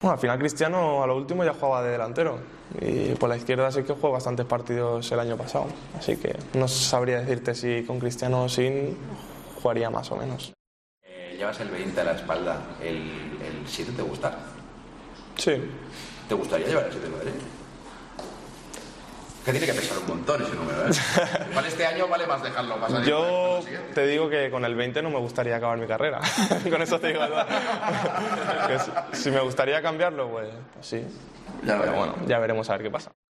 En una entrevista a Panenka, el joven centrocampista del Real Madrid y de la Selección ha declarado que sí le gustaría cambiar el dorsal de su camiseta.